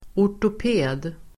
Uttal: [år_top'e:d]